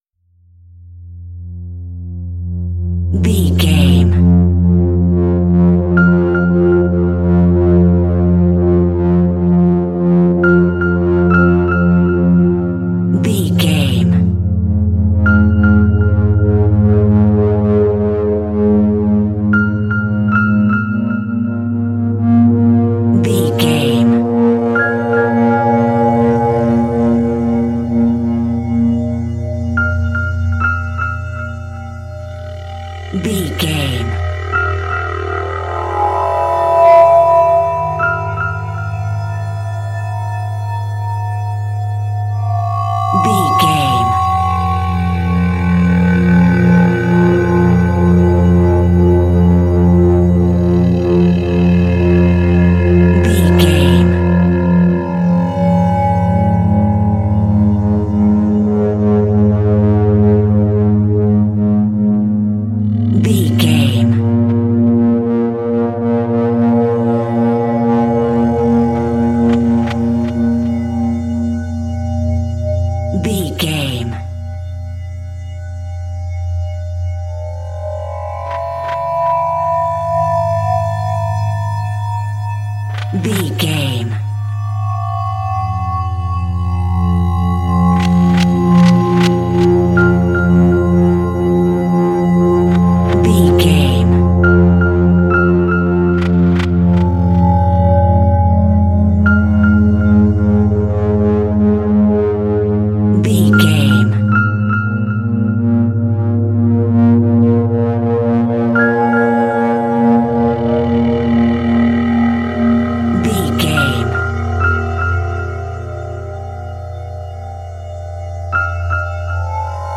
Aeolian/Minor
tension
ominous
dark
haunting
eerie
piano
Horror synth
Horror Ambience
synthesizer